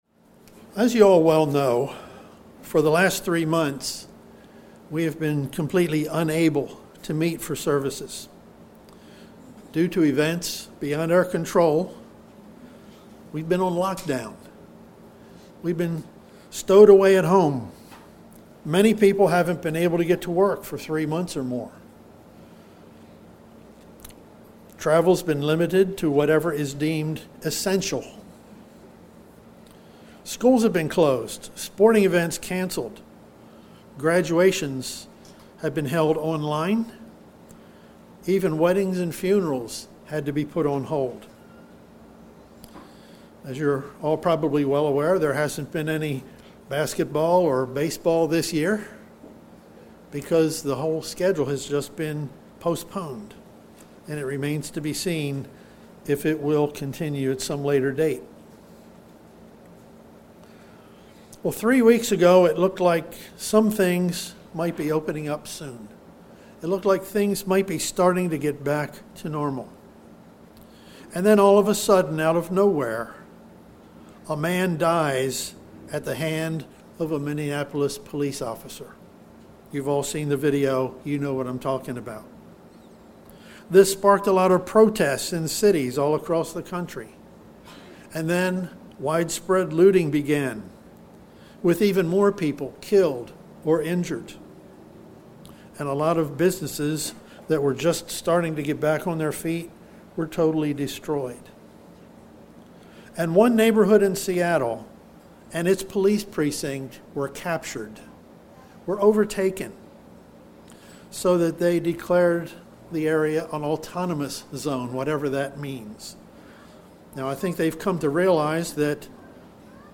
Given in Delmarva, DE